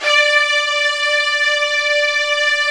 55u-va10-D4.aif